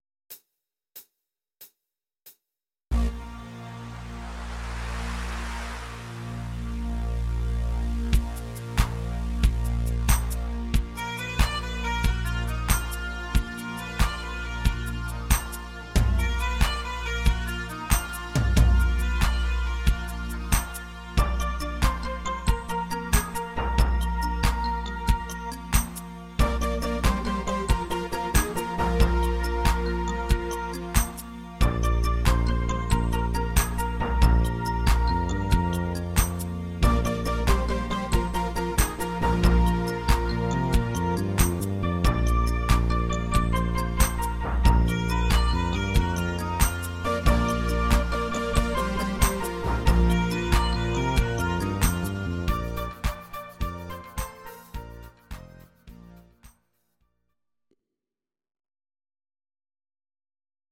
Audio Recordings based on Midi-files
German, Medleys